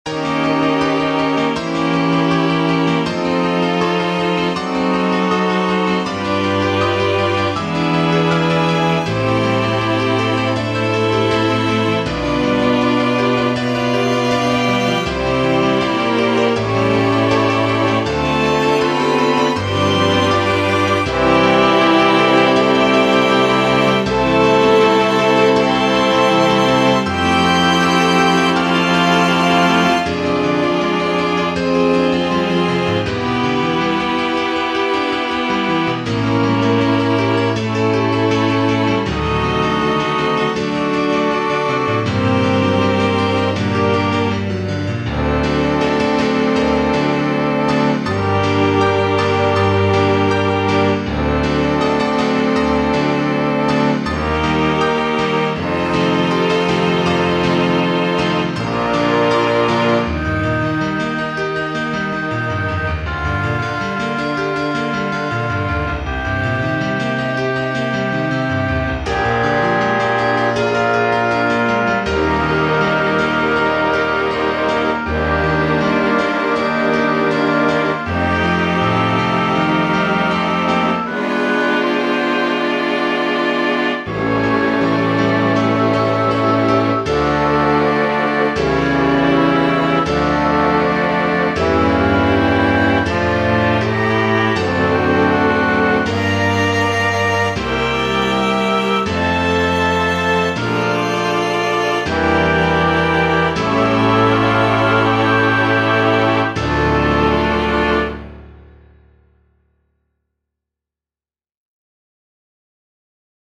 This evening I was improvising on the piano when I realised it might go better with some orchestral instruments.
Also, sorry for the fact that is no introduction at all, I just didn't have any inspiration left at that point lol.